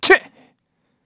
Kibera-Vox_Landing_kr.wav